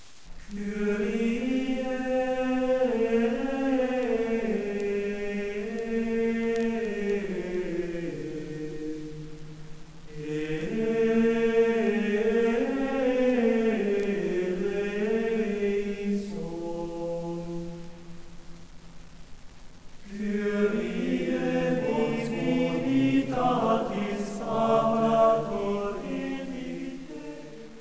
Mittelalter/Geistliche Kultur
Der Codex 9 der Universitätsbibliothek Graz wurde in der 1. Hälfte des 15. Jhs. geschrieben und stammt wahrscheinlich aus dem Zisterzienserstift Neuberg an der Mürz. Die Unterstimme des Kyrie wurde dem Choral entnommen, der neu komponierten Oberstimme wurde auch ein neuer Text ( Divinitatis amator) unterlegt, ein Verfahren, das man als Tropieren bezeichnet, den Text nennt man Tropus.